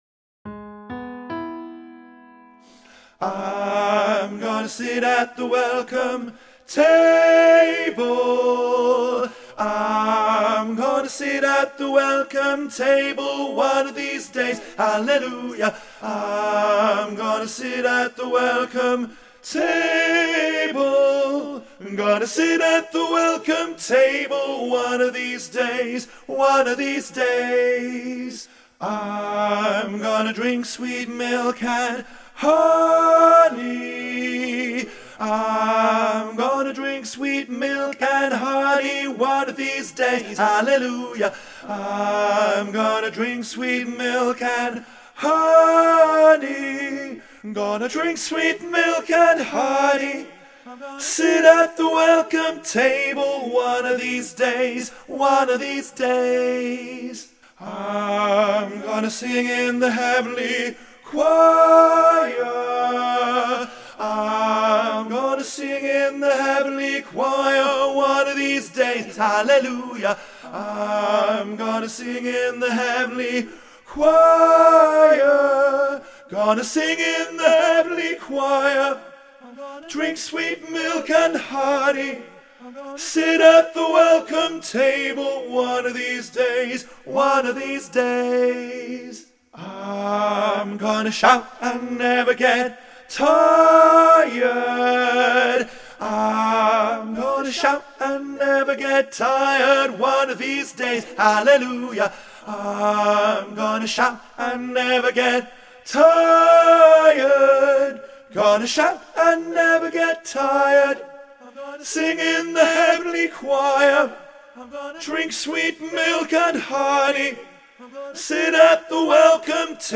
WELCOME-TABLE-TENOR
WELCOME-TABLE-TENOR.mp3